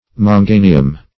manganium - definition of manganium - synonyms, pronunciation, spelling from Free Dictionary Search Result for " manganium" : The Collaborative International Dictionary of English v.0.48: Manganium \Man*ga"ni*um\, n. [NL.]